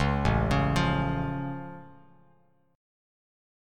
A+ Chord
Listen to A+ strummed